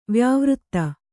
♪ vyāvřtta